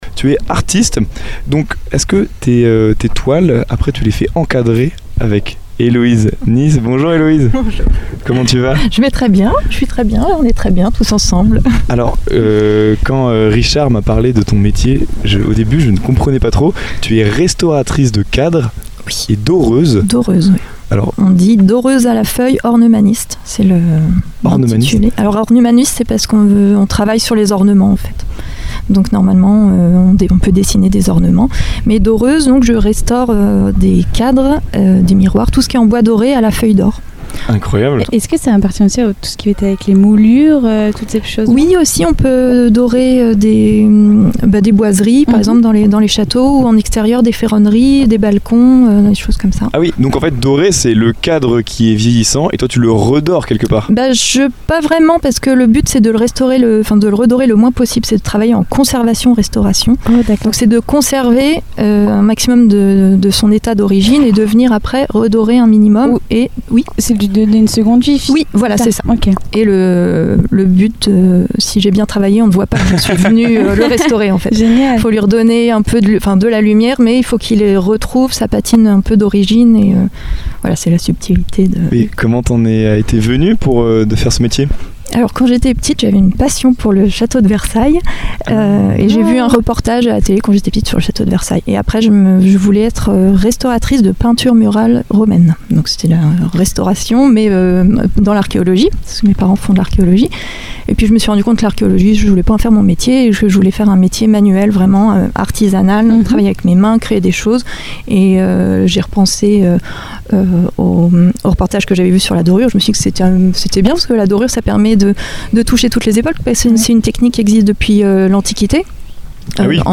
Une interview immersive et fascinante qui met en lumière un métier d’art exigeant, entre patrimoine, technique et sens du détail.